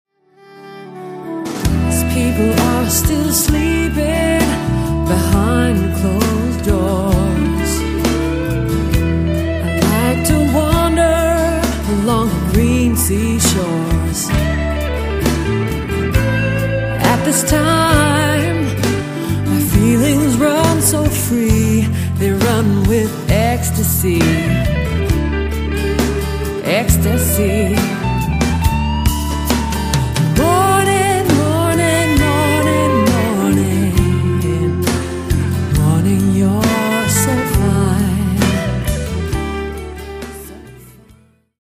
guitars, lead vocals
bass, backing vocals, hammond organ
drums & percussion